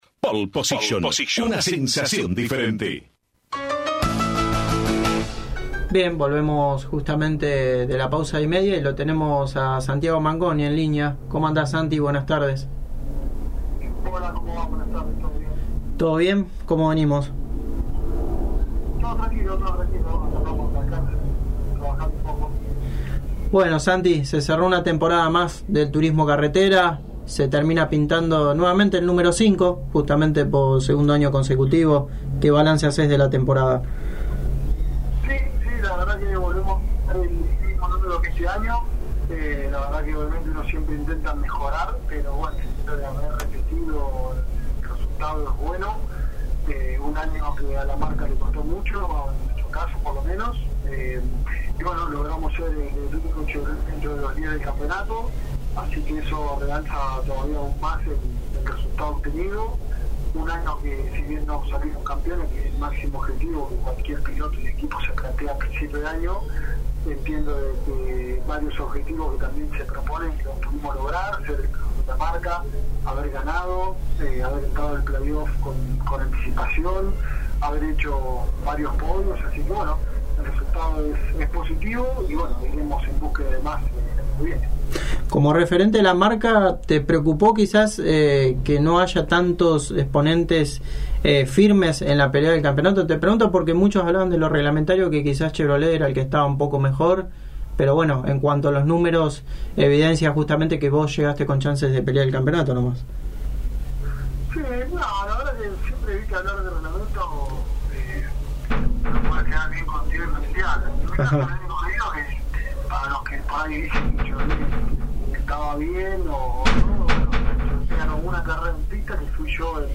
El piloto de Balcarce pasó por los micrófonos de Pole Position y evalúo como fue su temporada dentro del Turismo Carretera, siendo el mejor Chevrolet de la temporada y como sera el 2024 junto al JP Carrera.